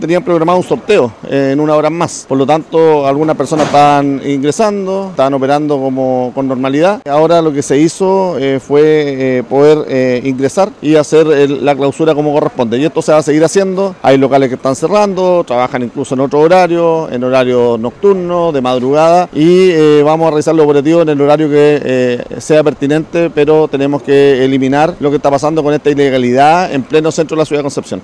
El alcalde dijo que el local funcionaba con total normalidad y que fue clausurado.